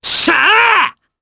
Gladi-Vox_Happy4_jp.wav